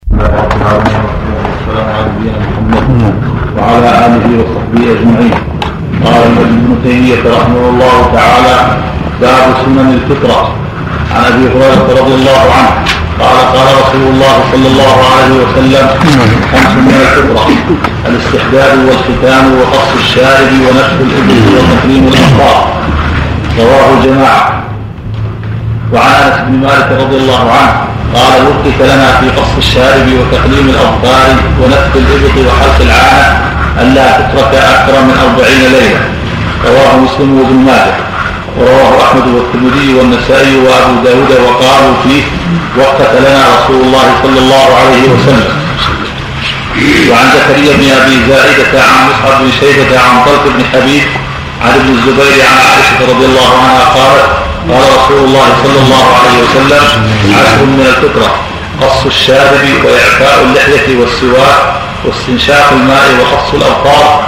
سلسلة محاضرات صوتية، وفيها تعليق الشيخ العلامة عبد العزيز بن باز - رحمه الله - على كتاب المنتقى من أخبار المصطفى - صلى الله عليه وسلم -، لمجد الدين أبي البركات عبد السلام بن تيمية الحراني - رحمه الله -.